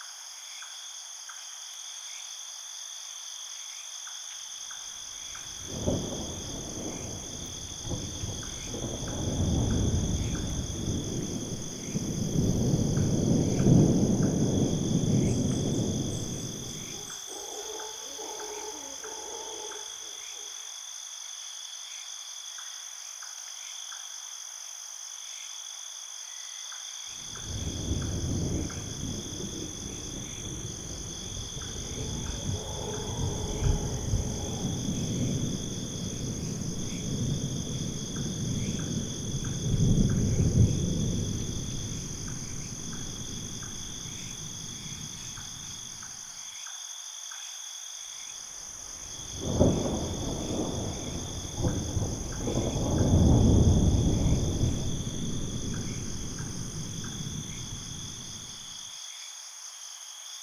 JungleAmbienceNightThunder.ogg